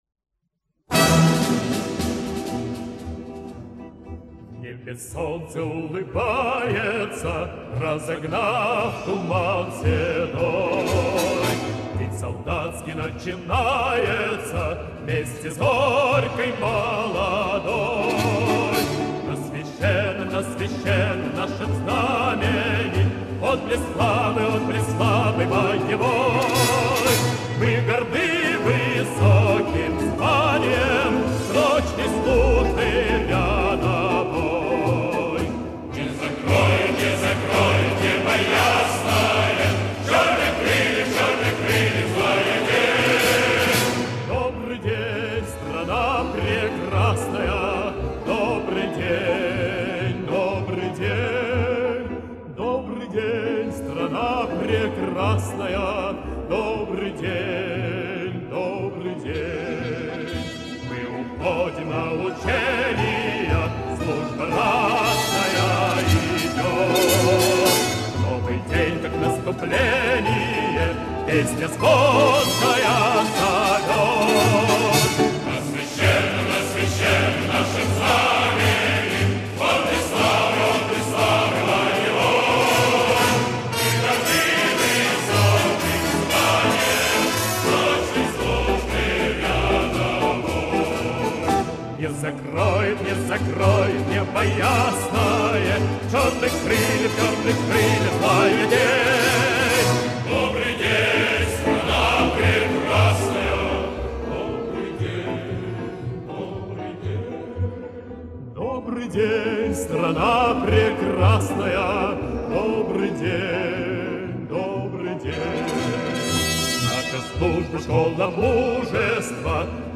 Патриотичная песня о защитниках страны